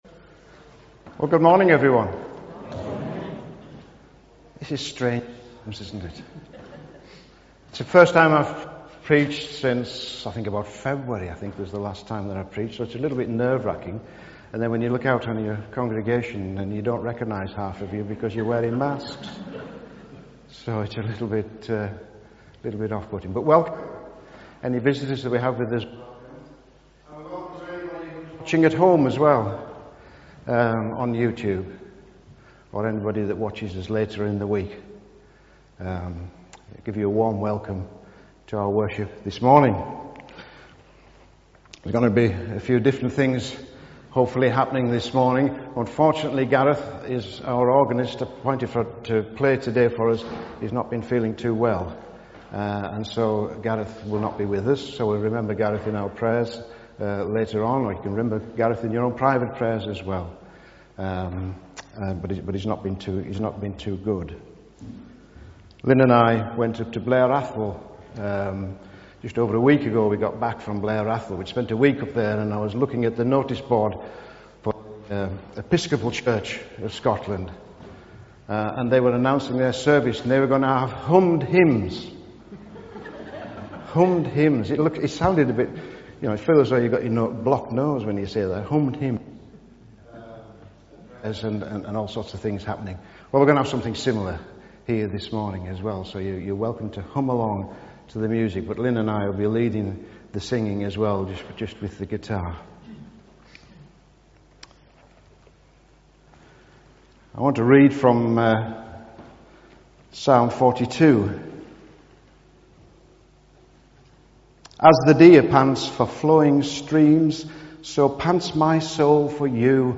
A message from the service
From Service: "10.30am Service"